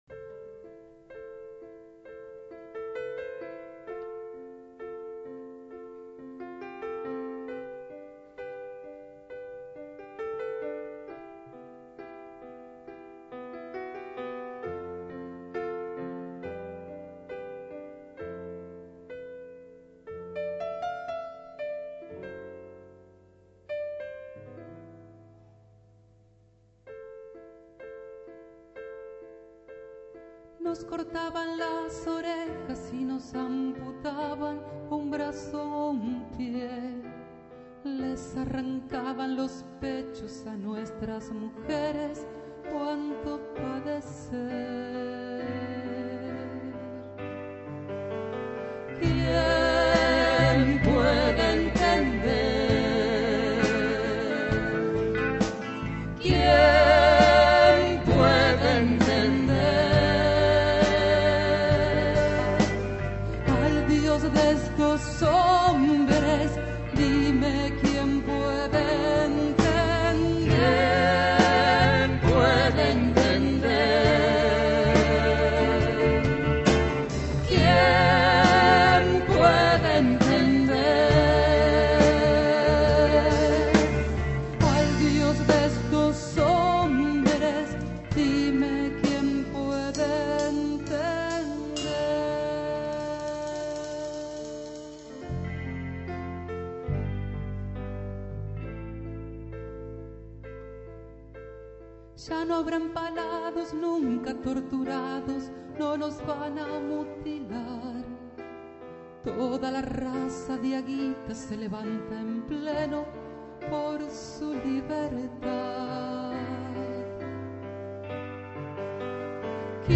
voz, guitarra y charango
aerófonos y djembe